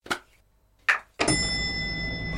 windows-ding.mp3